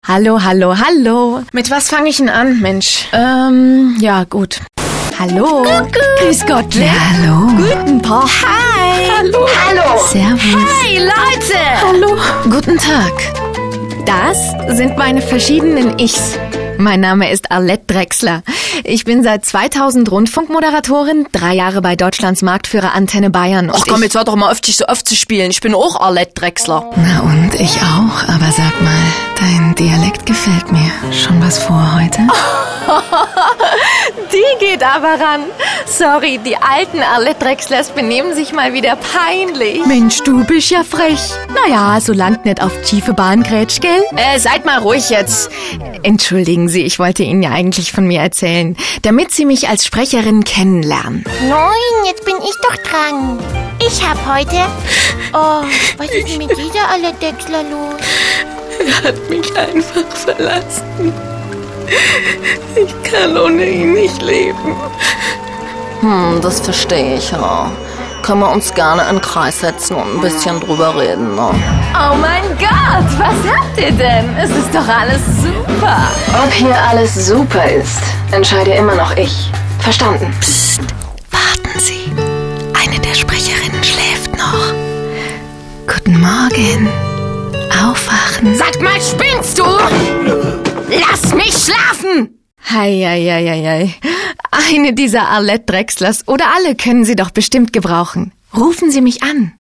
- Extrem wandelbare Stimme!
Außergewöhnlich vielfältige Sprecherin.
Sprechprobe: Werbung (Muttersprache):